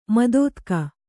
♪ madōtka